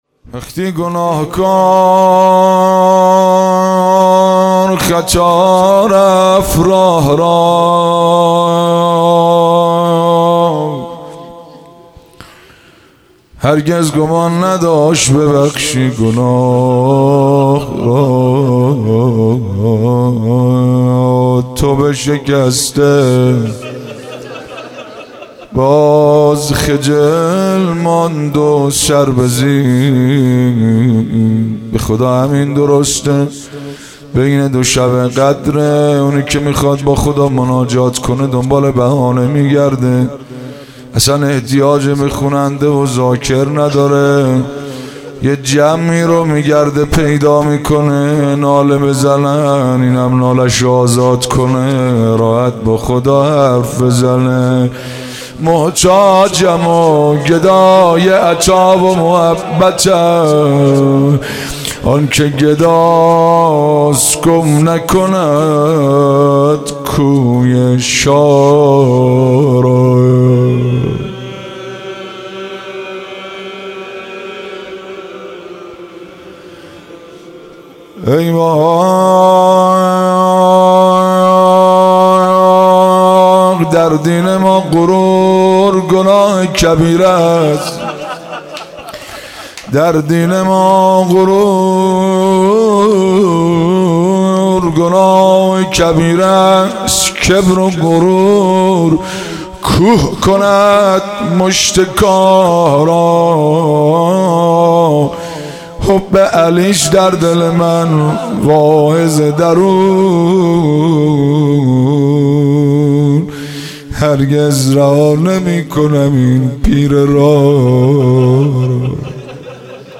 مناسبت : شب بیست و دوم رمضان
قالب : مناجات